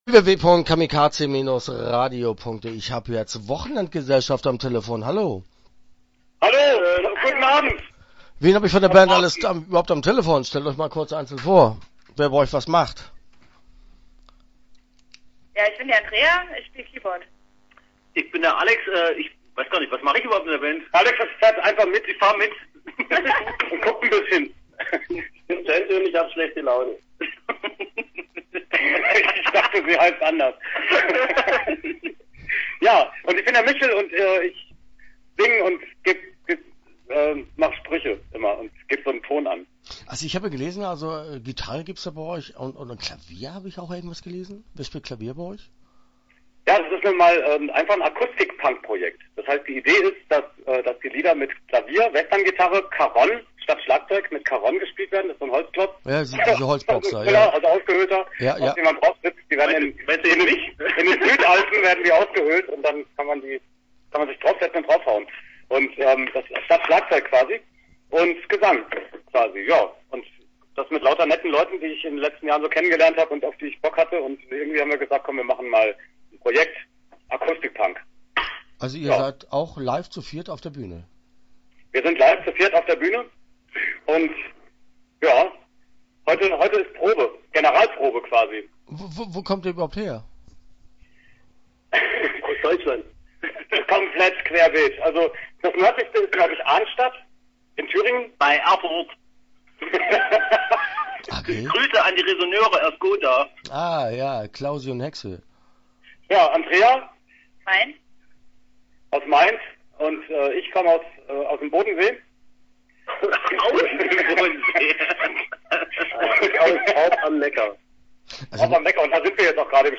Interview Teil 1 (10:47)